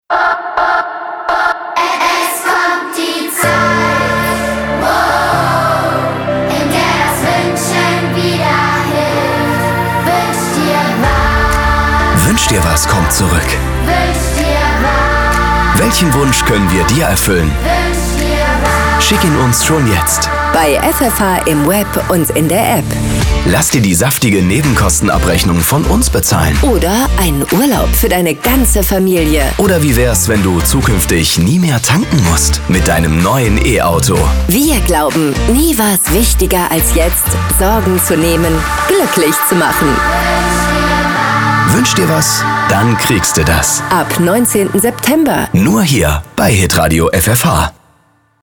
Die Stationvoice für Ihren Sender: prägnant, unverwechselbar, formatsicher.